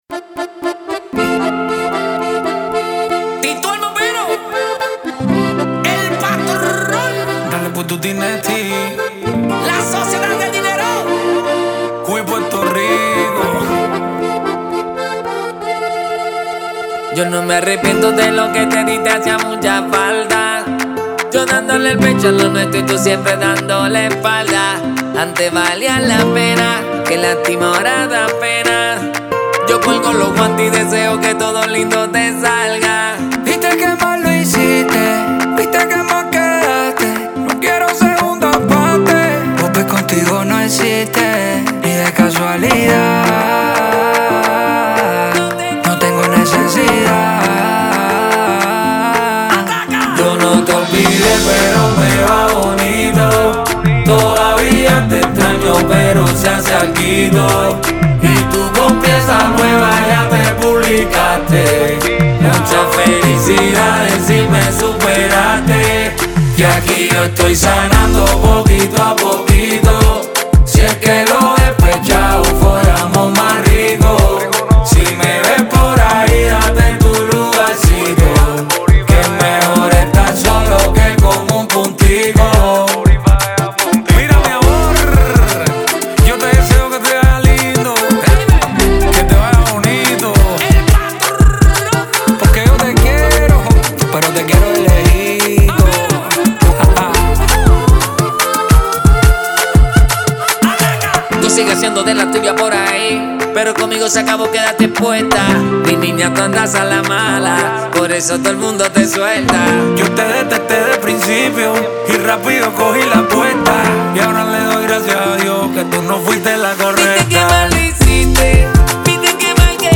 Con una propuesta fresca dentro del reparto cubano